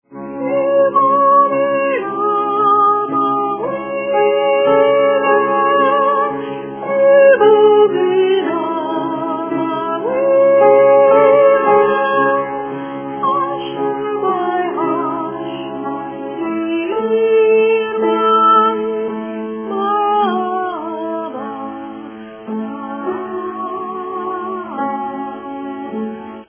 S: sopranen, A: alten, T: tenoren, B: bassen.